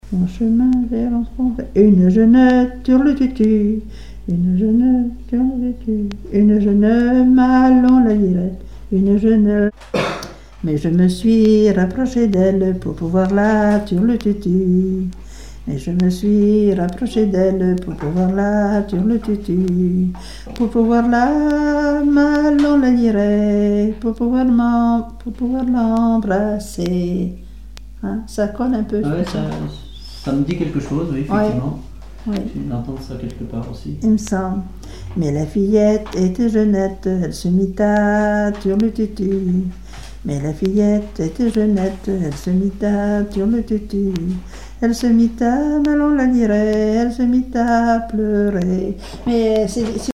Genre laisse
Répertoire de chansons traditionnelles et populaires
Catégorie Pièce musicale inédite